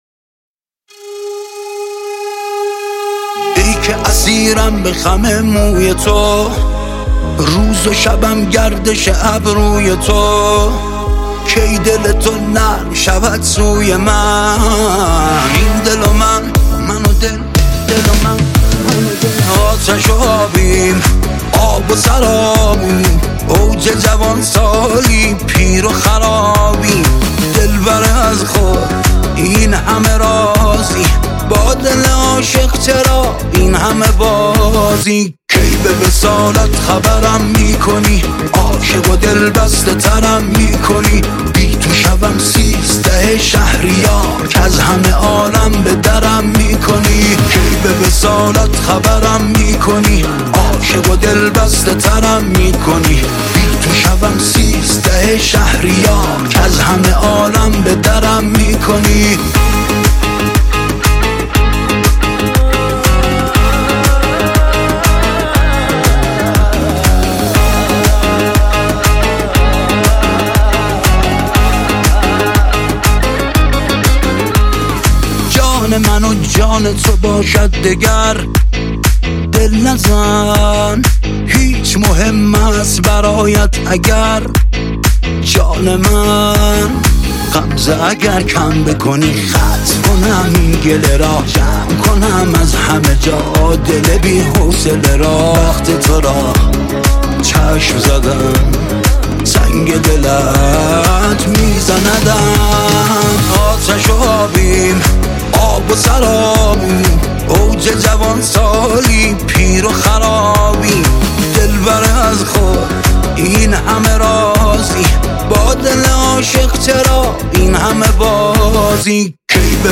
زده تو سبک چاوشی دیگه
با این آهنگ آدم شور سه ضرب هیئت محرم و سینه زدن رو میگیره ?